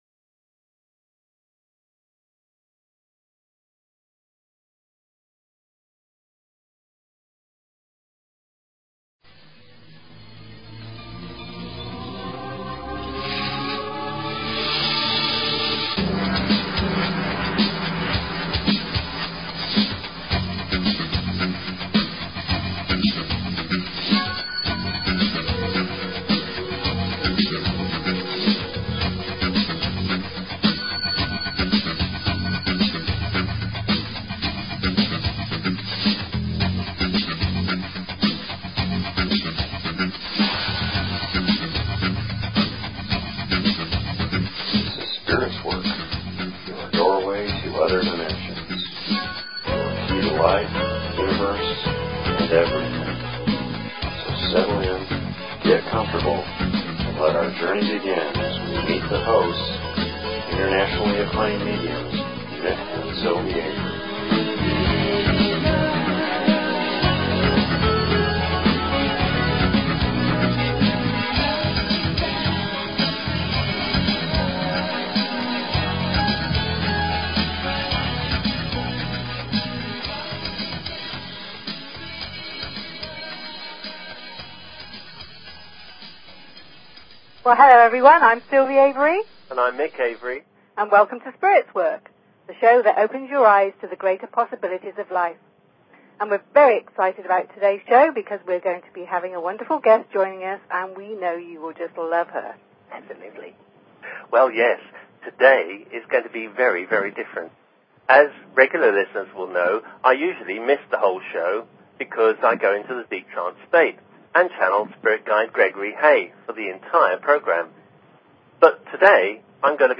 Talk Show Episode, Audio Podcast, Spirits_Work and Courtesy of BBS Radio on , show guests , about , categorized as
Live Readings & Interview